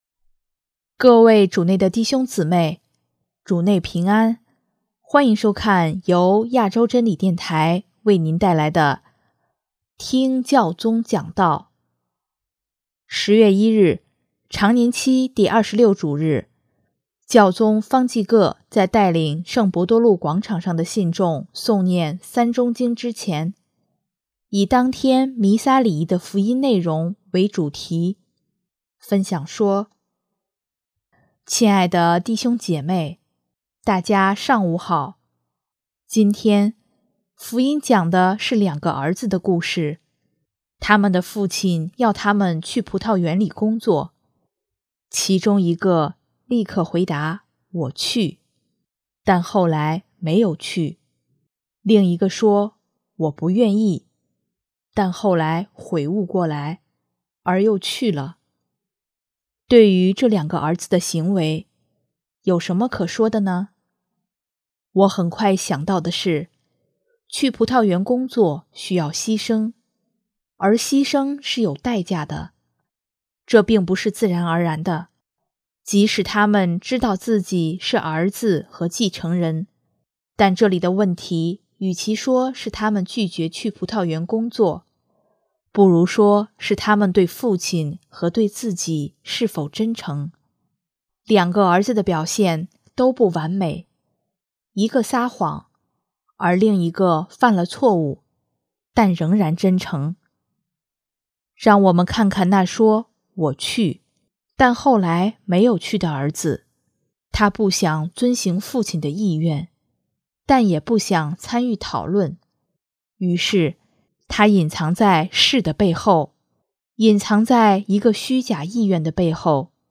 【听教宗讲道】|我们是罪人，但我们不要生活在伪善中
10月1日，常年期第二十六主日，教宗方济各在带领圣伯多禄广场上的信众诵念《三钟经》之前，以当天弥撒礼仪的福音内容为主题，分享说：